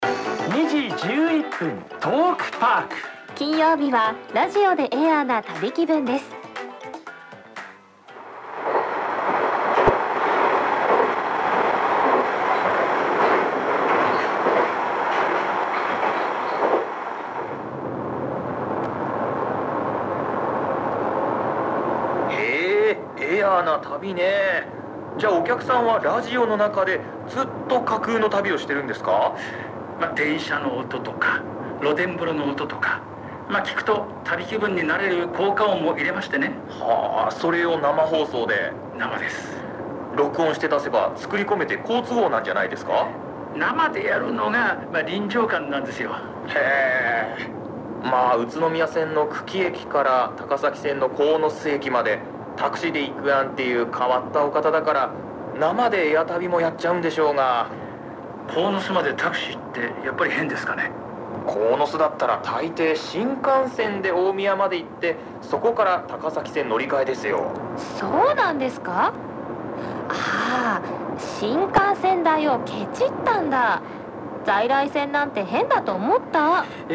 歪音が苦手な方は，録音データを再生しないようお願いいたします・・・。
録音データその４　93.5MHZ（2024-08-20自宅　1SS108×３パラ　0.1μF）